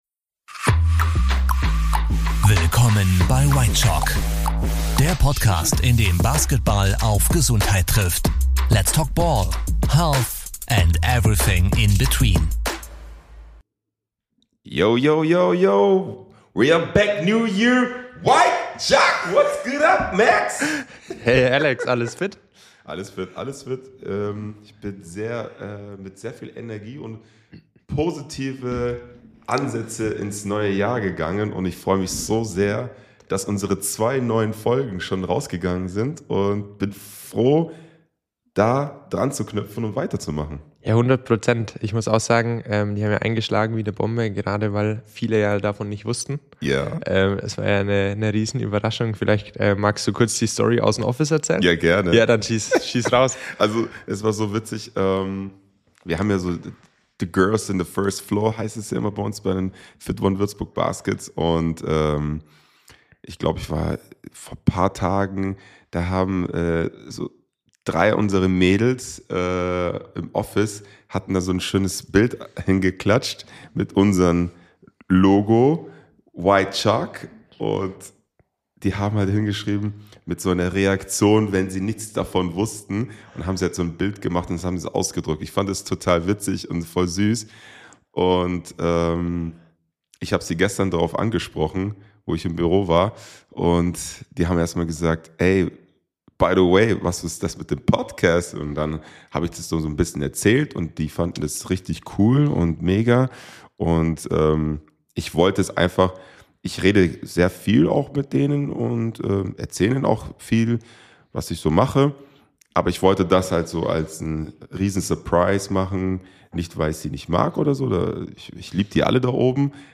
Erwartet ehrliche Gespräche, eine ordentliche Prise Humor und tiefe Einblicke in die Welt von Sport, Gesundheit und Lifestyle.